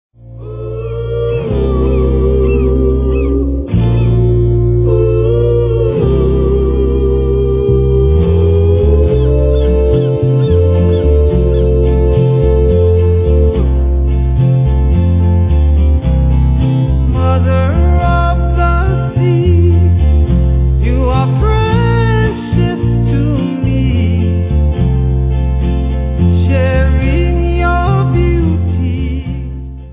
Contemporary Music